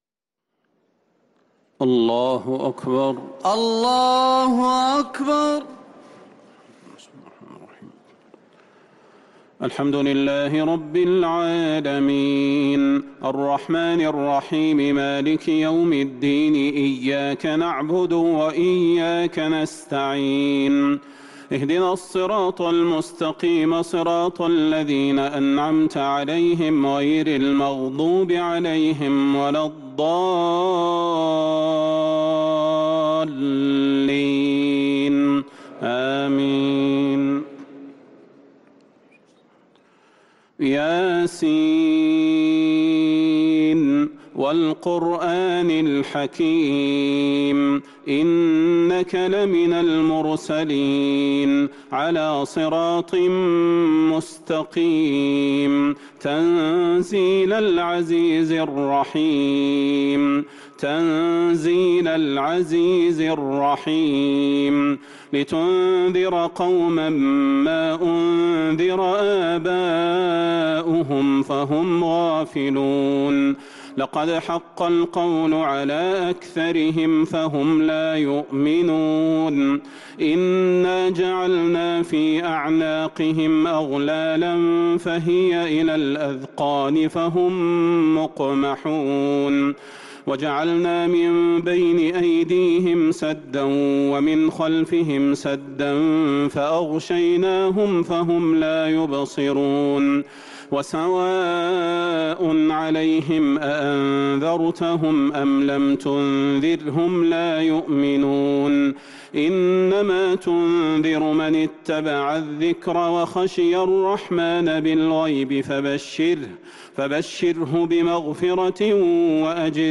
صلاة التراويح ليلة 25 رمضان 1443 للقارئ صلاح البدير - التسليمتان الأخيرتان صلاة التراويح